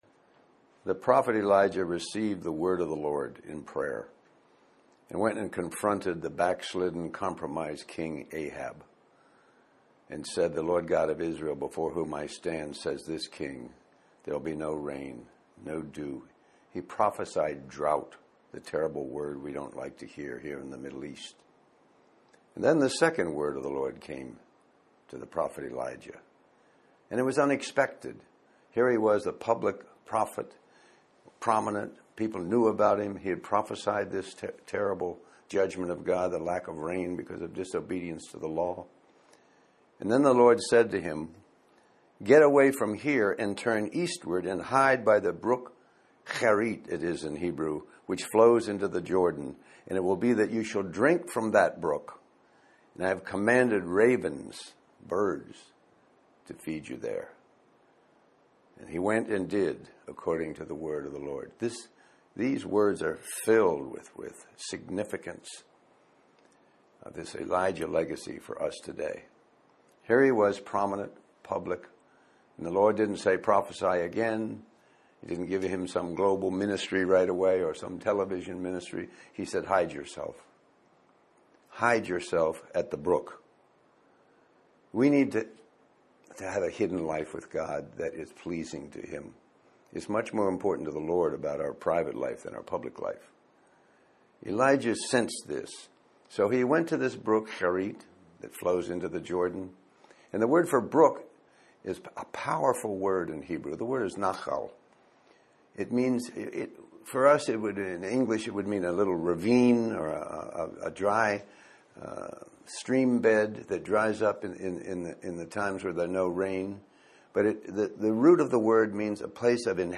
Ultimately, the sermon calls for a commitment to a private life of prayer and communion with God, which is essential for effective public ministry.